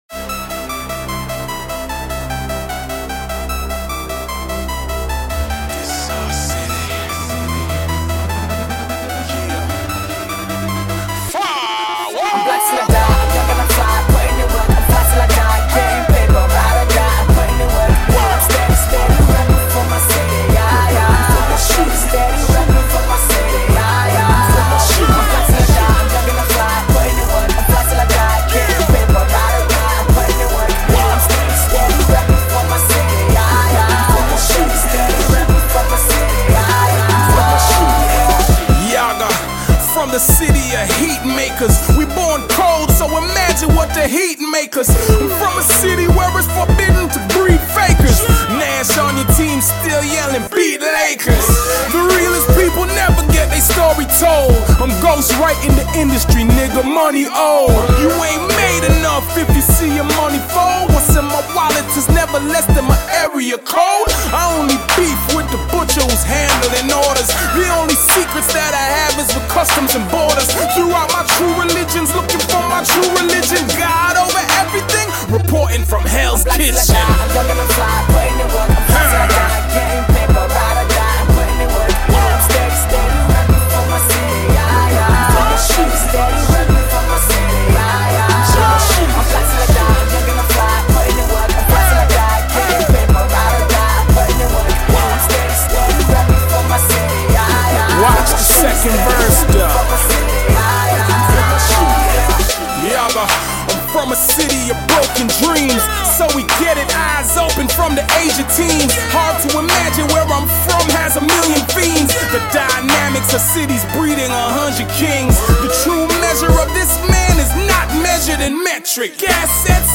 Canadian rapper